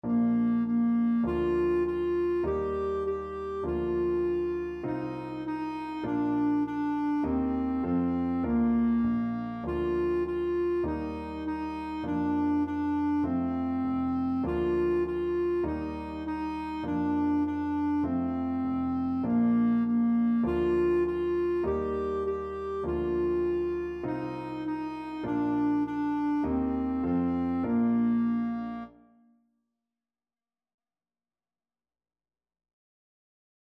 4/4 (View more 4/4 Music)
Bb4-G5
Lullabies for Clarinet